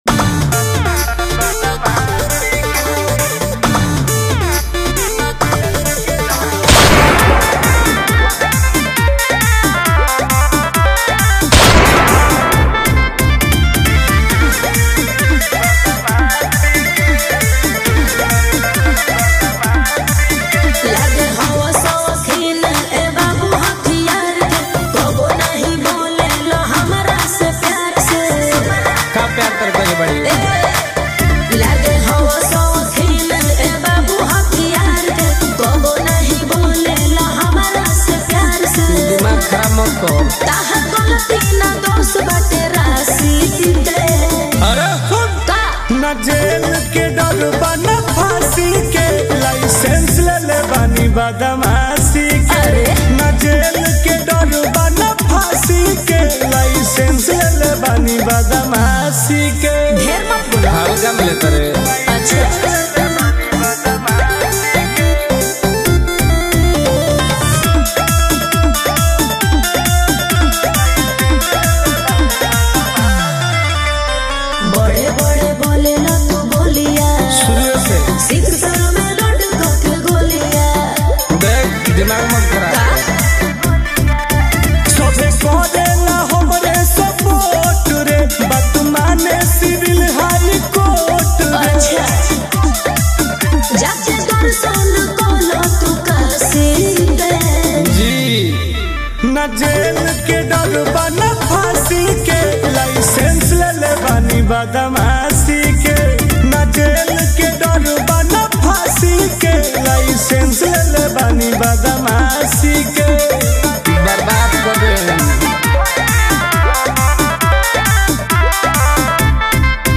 Releted Files Of New Bhojpuri Song 2025 Mp3 Download